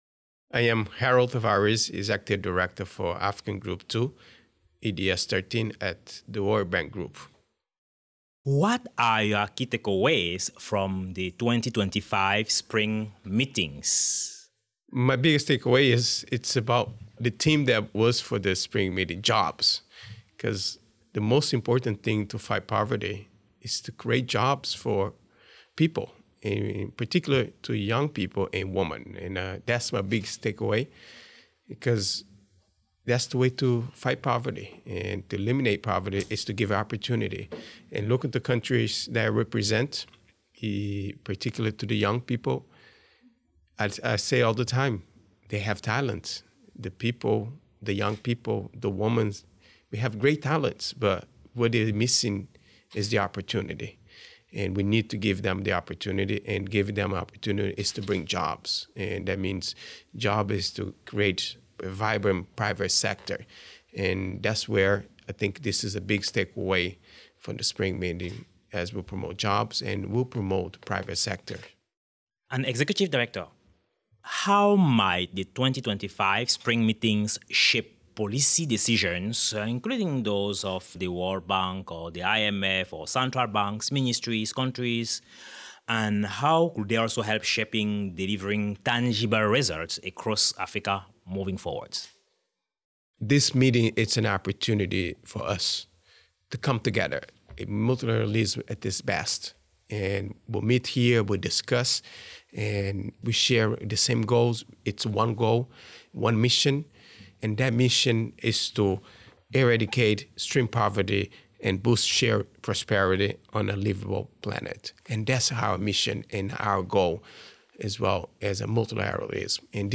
Foresight Africa podcast at the 2025 World Bank/IMF Spring Meetings
In this interview, he speaks with Harold Tavares, executive director for Africa Group II at the World Bank Group, representing 23 countries.